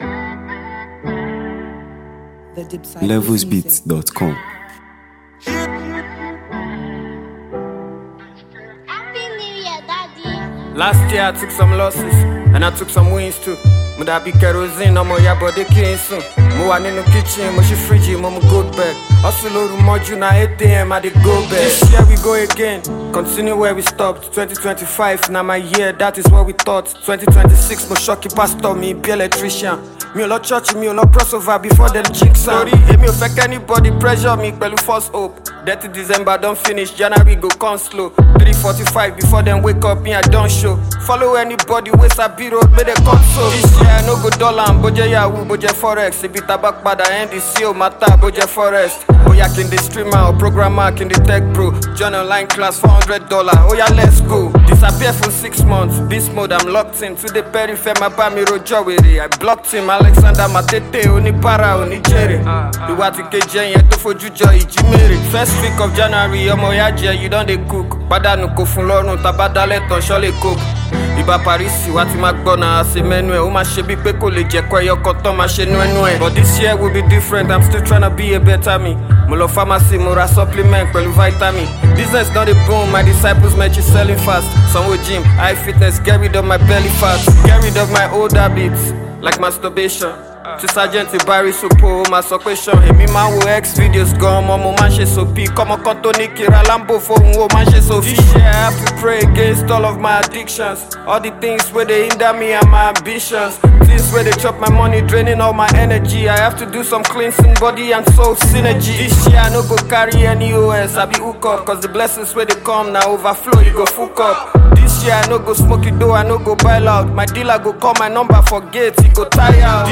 Nigeria Music 2026 3:35